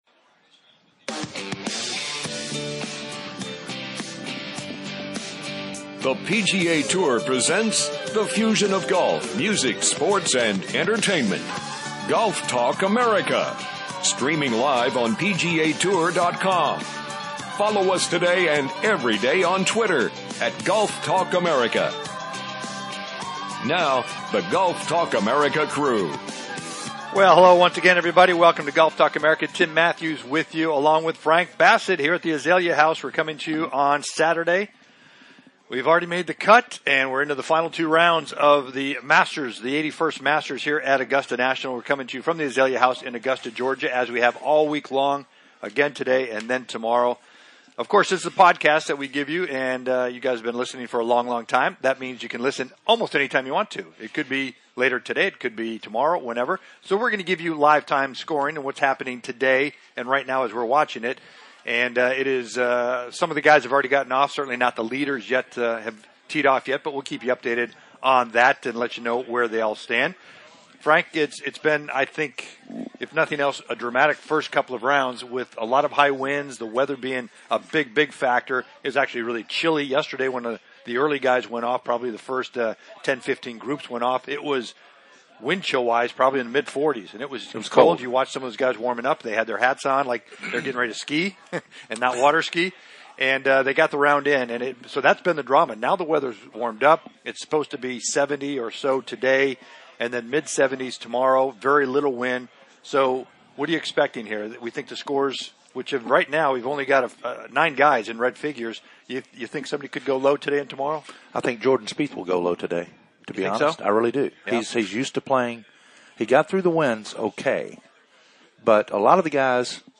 "LIVE" FROM THE MASTERS....